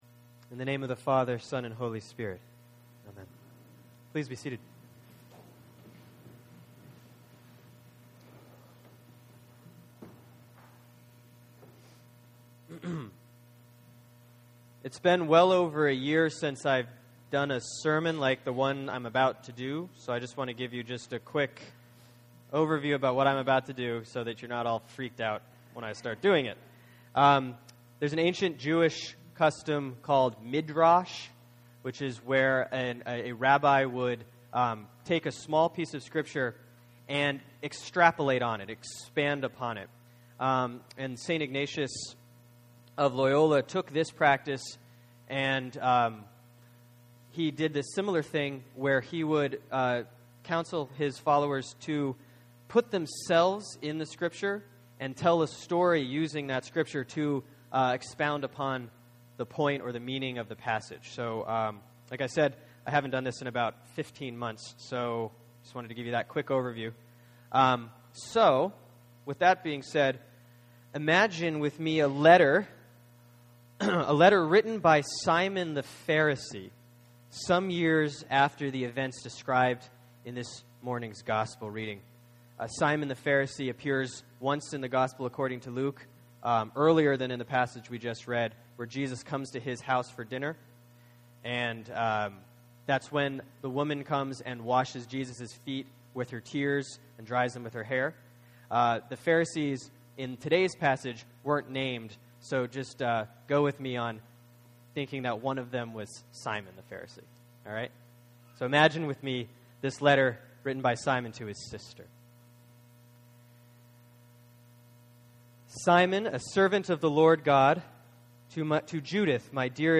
(Sermon for Sunday, February 24, 2013 || Lent 2C || Luke 13:31-35)